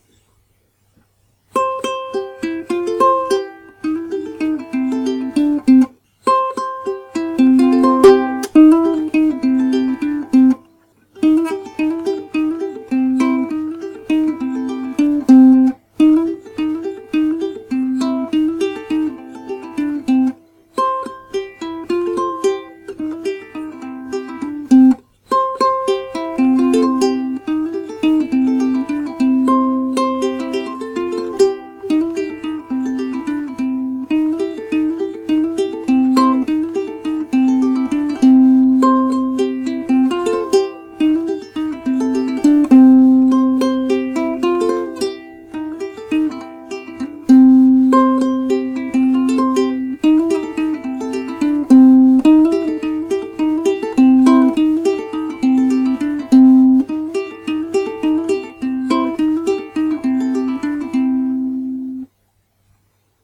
(In Ermangelung eines geübten Weihnachtsstückes ein paar Takte Cripple Creek auf der Ukulele:
Noch nicht ganz sauber, aber ich bin ja auch noch Lerner, deshalb auch das große rote L an der Seite in meinem Blog.
Vielen Dank für das Ukulele-Stück, das hört sich schon sehr gut an!